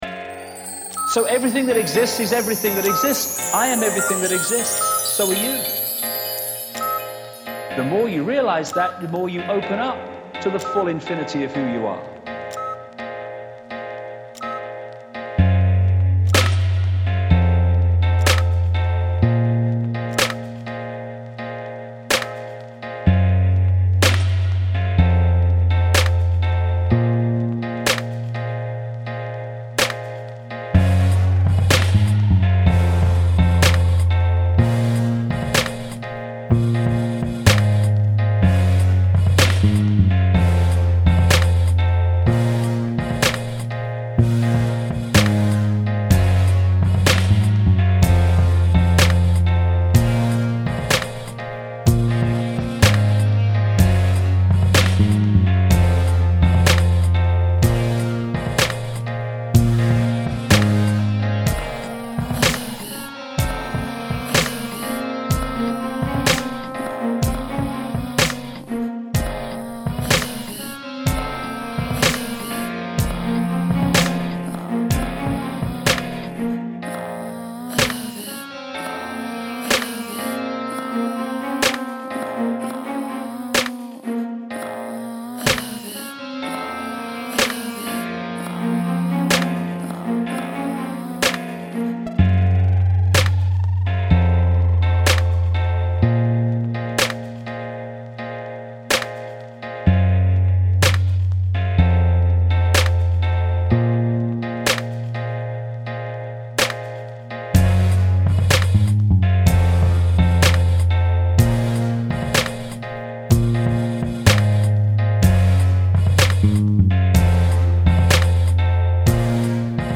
A collective piece comprising of an abstract painting, poem & a musical instrumental.